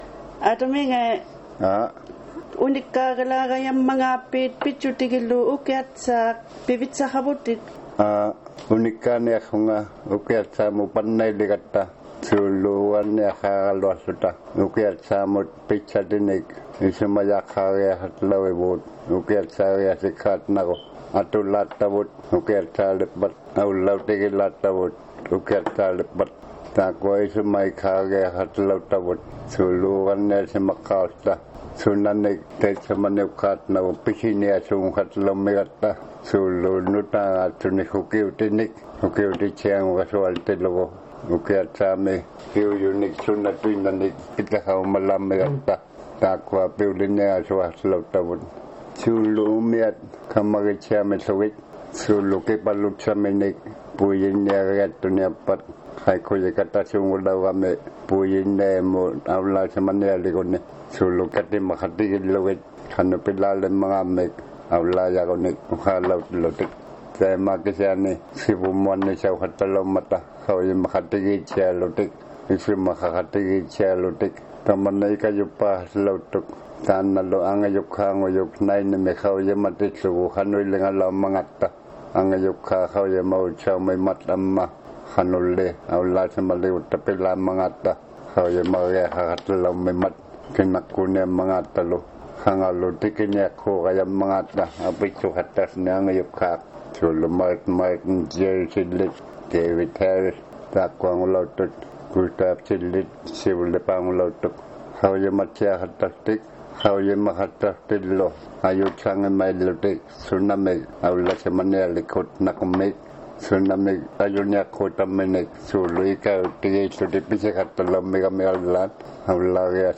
There are many interesting Inuktitut stories out there, when Inuit Elders tell their own stories.